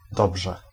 Ääntäminen
IPA: /ˈhyvin/